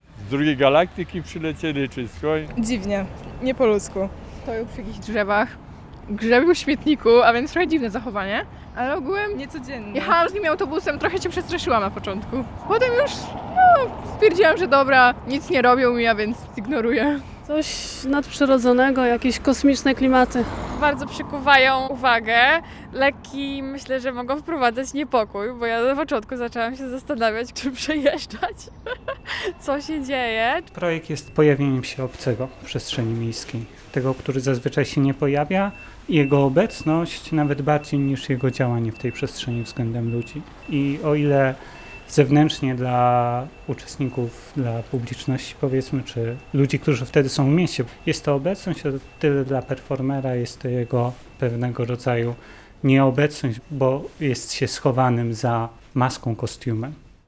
Kosmici w centrum Białegostoku - jeden z elementów festiwalu "Kalejdoskop" - relacja